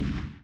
mortar impact.mp3